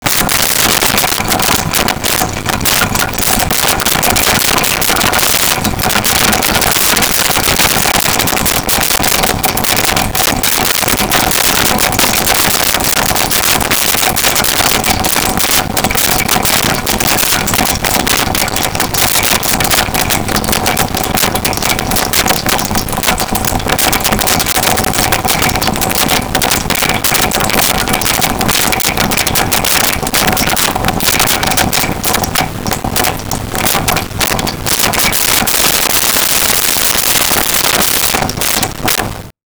Water Drain 2
water-drain-2.wav